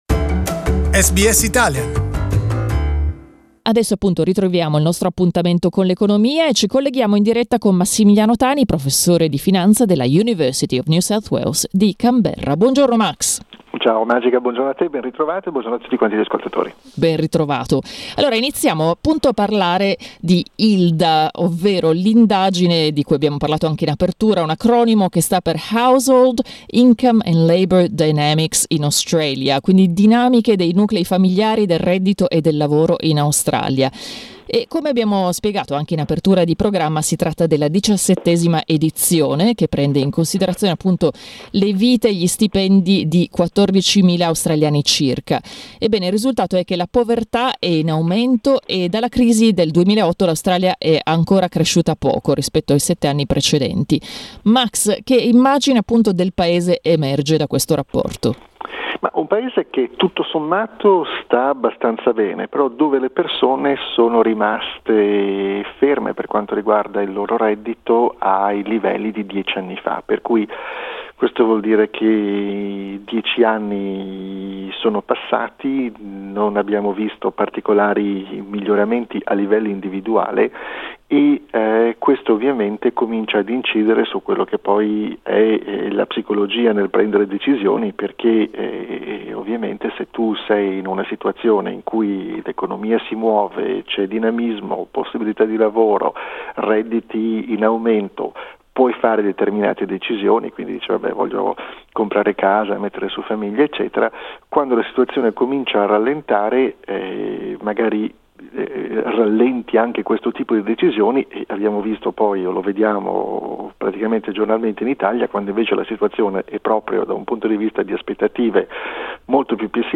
Poverty in Australia is one of the topics discussed in our weekly conversation with UNSW finance professor